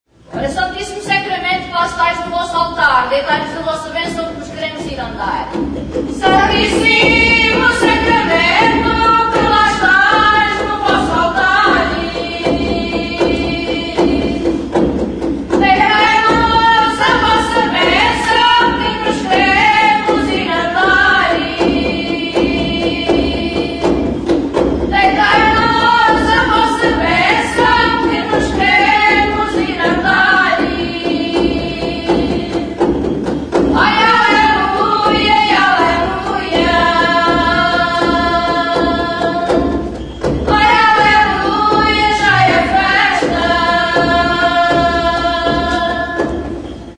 Instruments de musiqueADUFE
Membranophones -> Frappés -> Tambourins
ADUFEIRAS. 'Las Adufeiras do Rancho Etnográfico de Idanha-a-Nova'. Valencia de Alcantara, 2006-05-14
Lauki formako panderoa da.